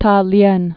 (tälyĕn)